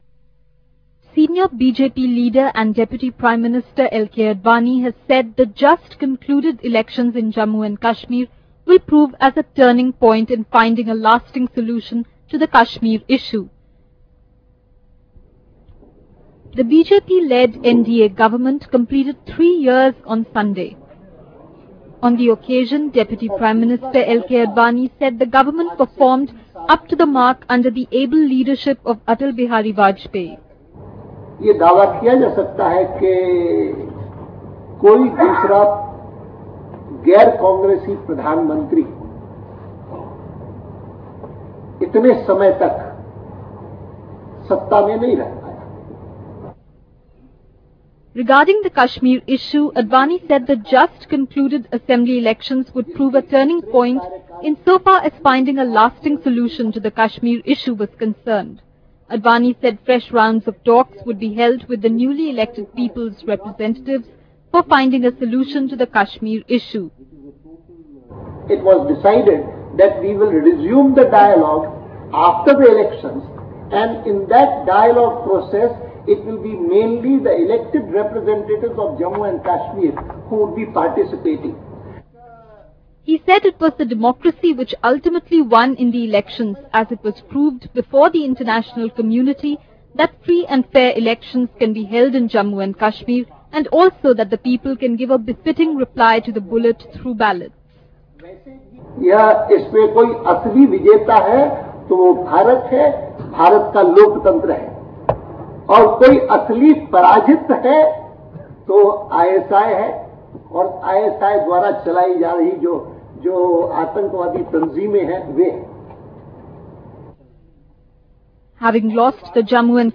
56k)   Deputy Prime Minister L.K. Advani addresses a Press conference